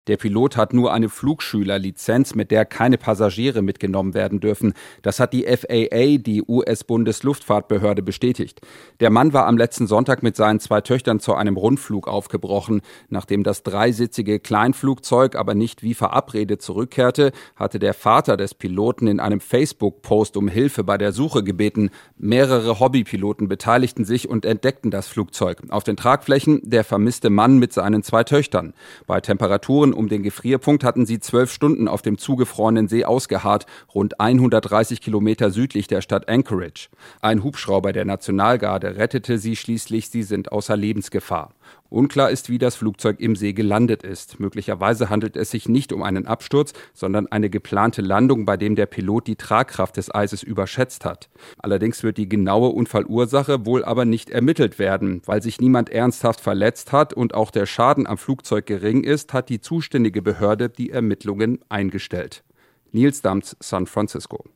Nachrichten Rettung aus zugefrohrenem See: Pilot hätte Töchter nicht mitnehmen dürfen